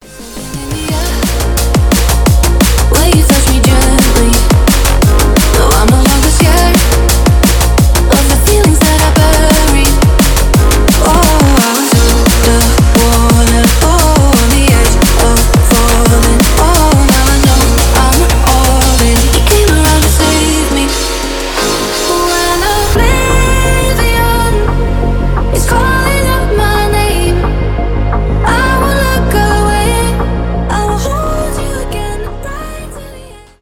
дабстеп , красивый женский голос
драм энд бейс